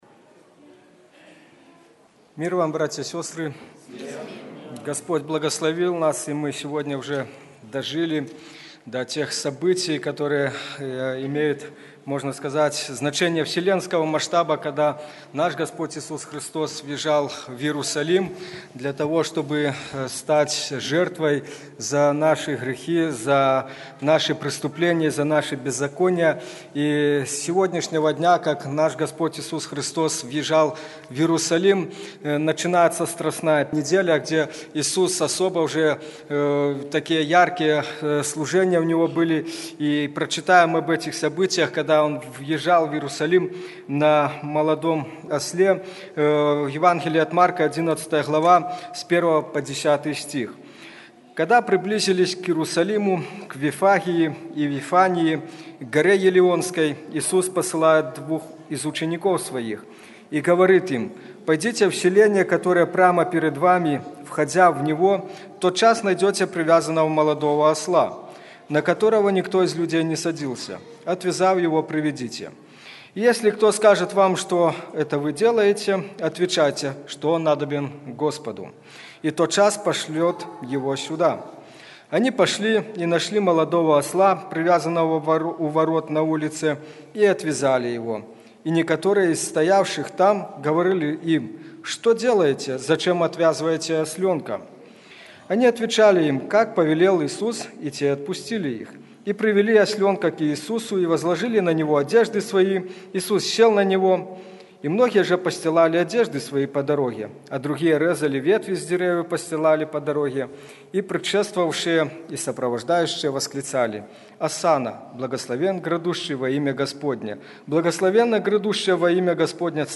Церковь евангельских христиан баптистов в городе Слуцке